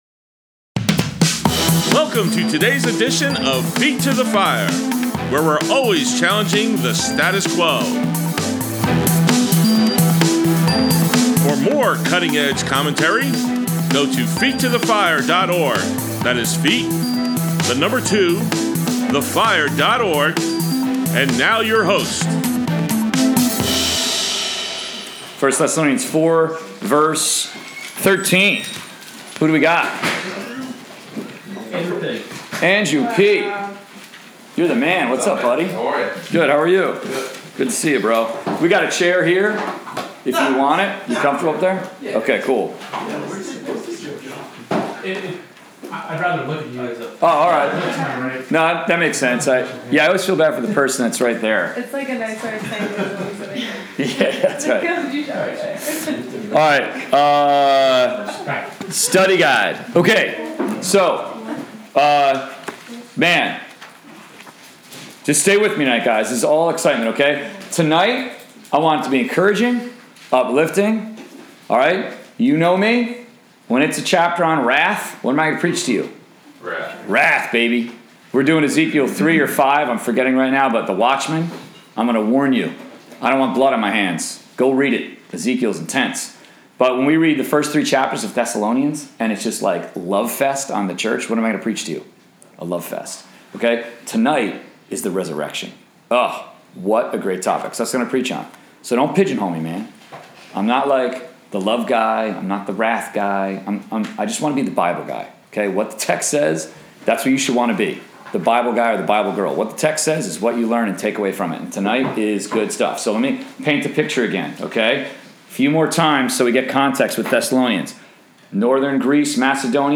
College/Career Bible Study, May 6, 2017: Part 1 of 2